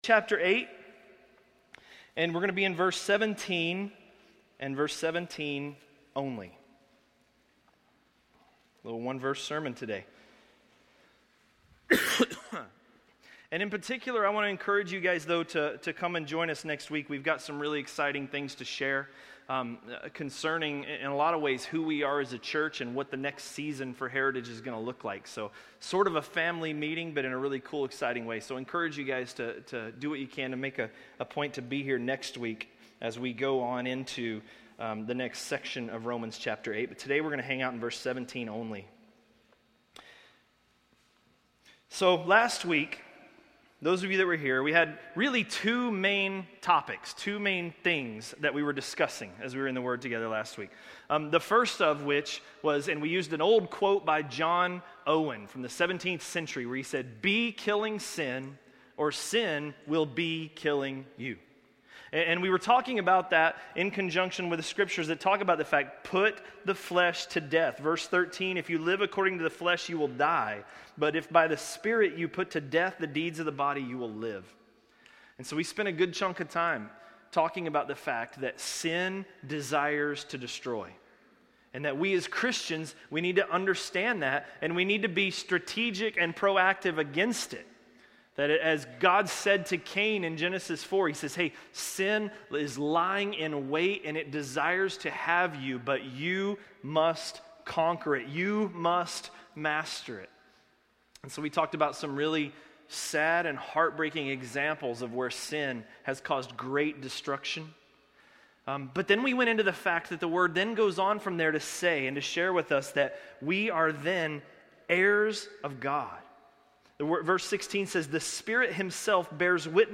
A message from the series "Romans." Romans 8:17